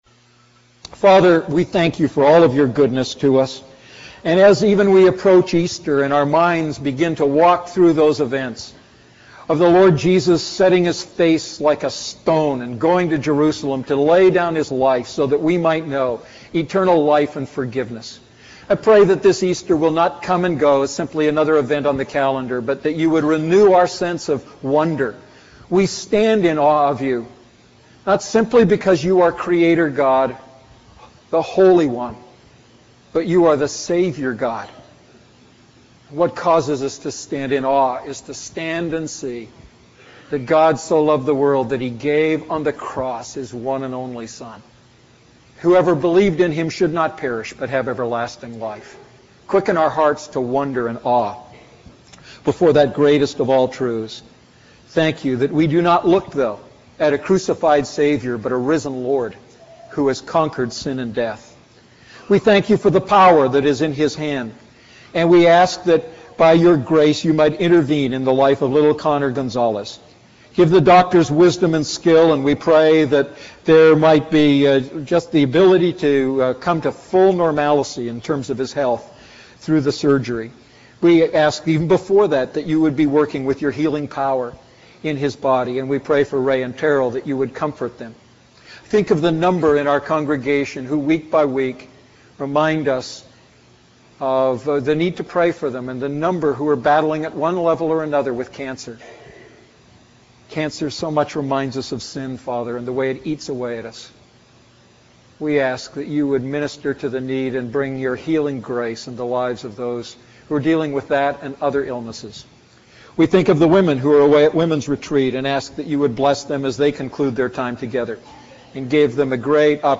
A message from the series "Selected Psalms."